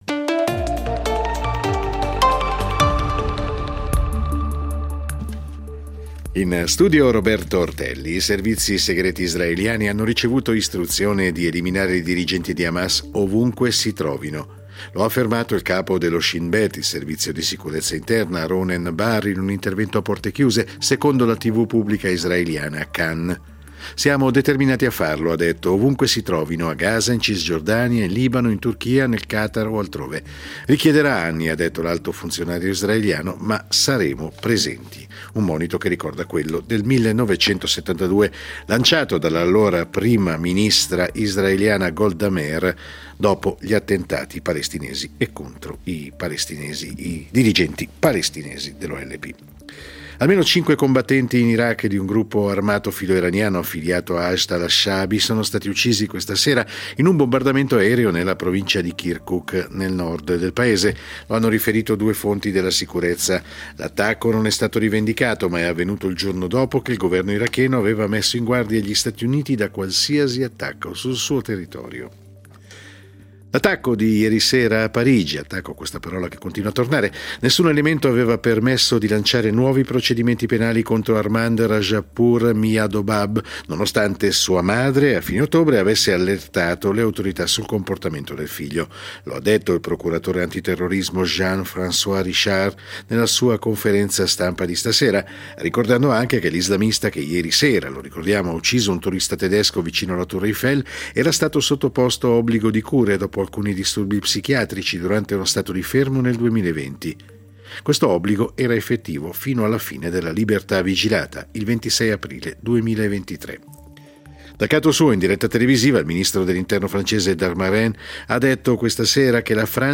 Notiziario delle 21:00 del 03.12.2023